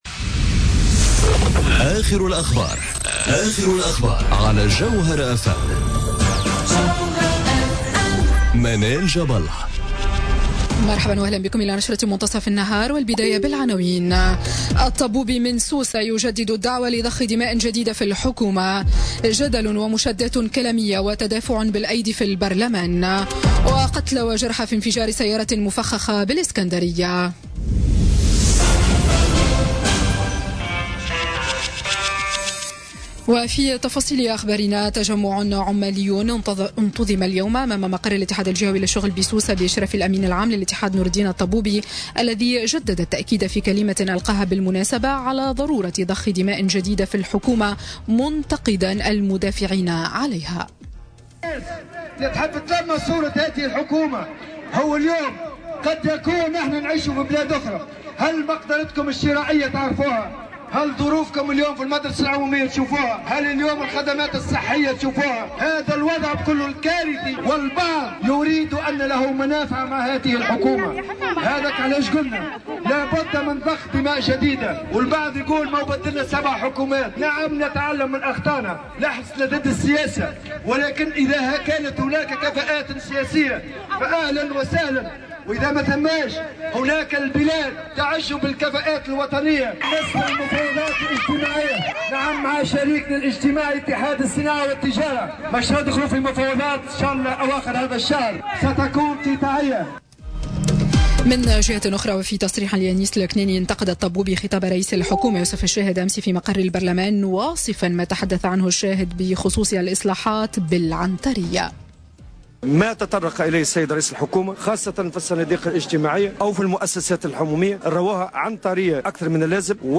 نشرة أخبار منتصف النهار ليوم السبت 24 مارس 2018